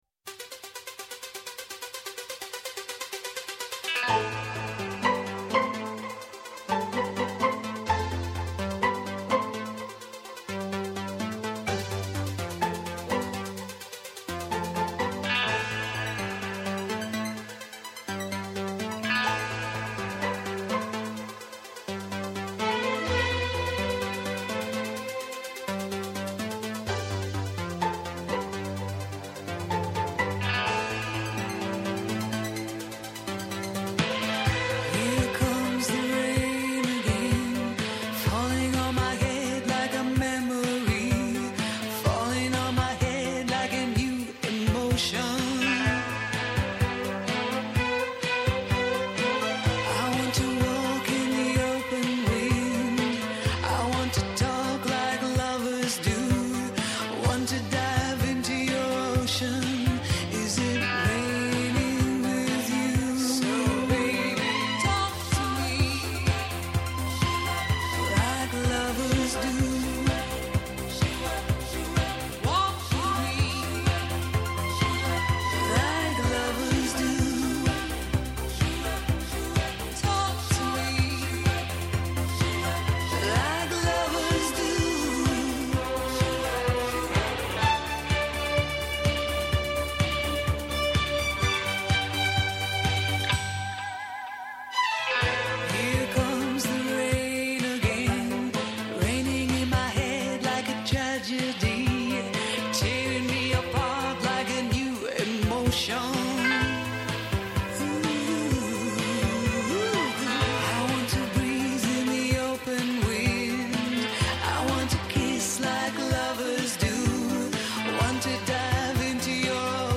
Σήμερα αφιέρωμα στο 29ο Διεθνές Φεστιβάλ Χορού Καλαμάτας με καλεσμένους :